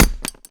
grenade_hit_carpet_hvy_01.wav